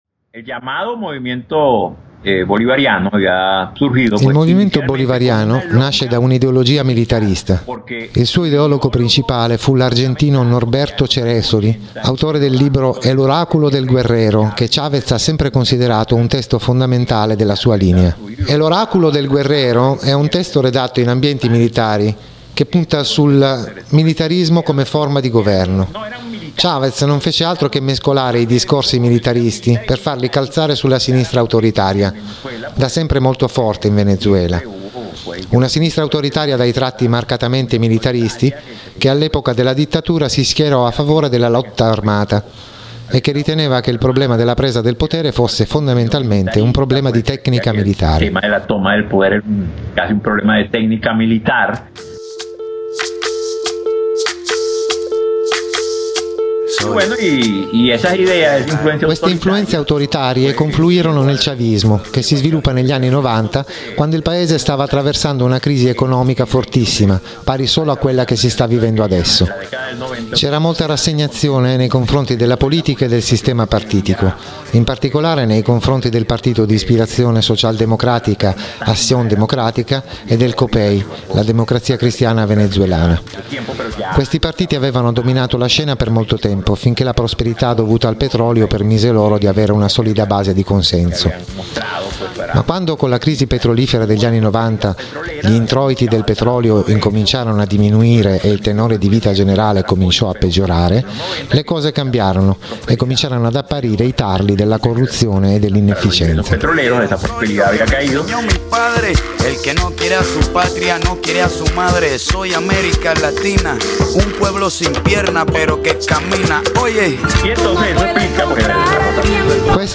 In collegamento con un compagno venezuelano, un contributo in cui non si parla di ciò che sta accadendo, ma di ciò che conviene sapere per inquadrate ciò che sta accadendo.